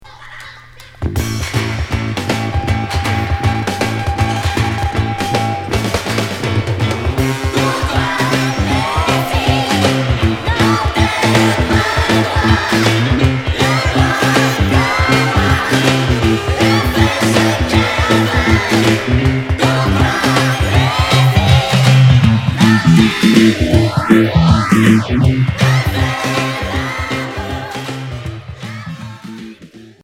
Rock psychédélique Premier 45t retour à l'accueil